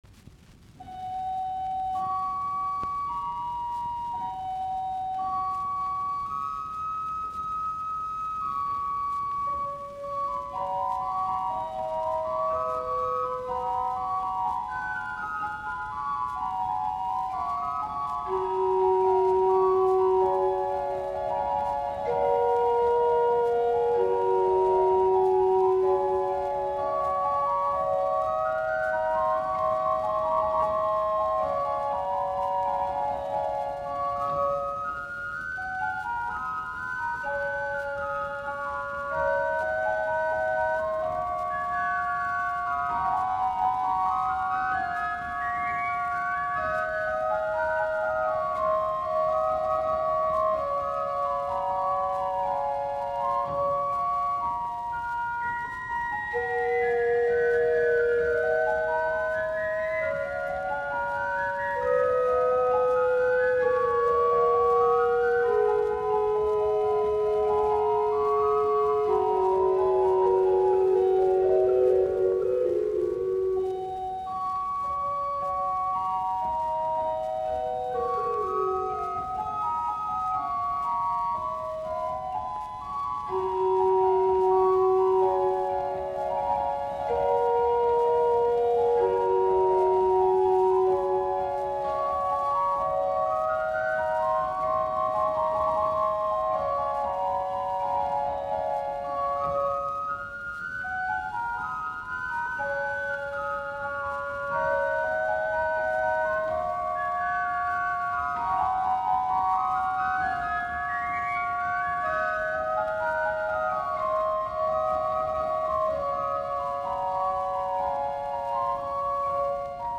Koraalit, urut
Soitinnus: Urut.
Neuenfelde, Neuenfelde Kirche.